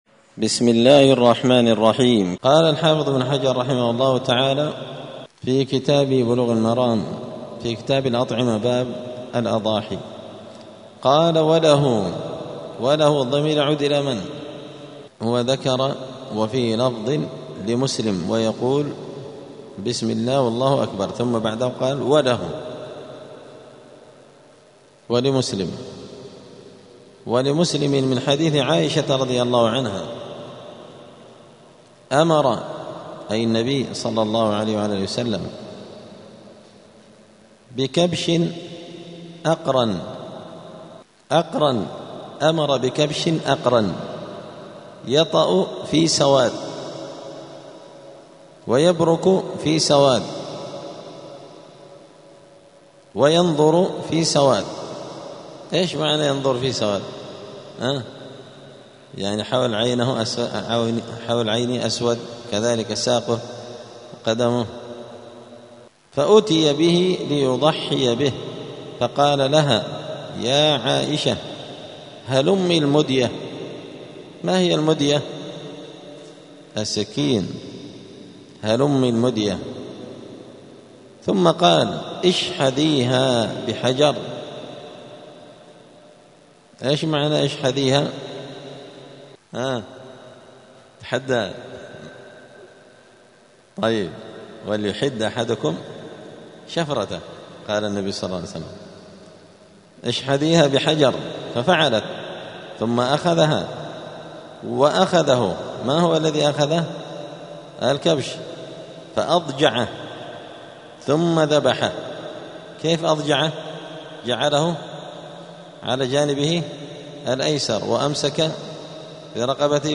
*الدرس الواحد والعشرون (21) {طريقة ذبح النبي لأضحيته}*